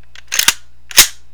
Weapons Sound Effects Library
riflecock-1.wav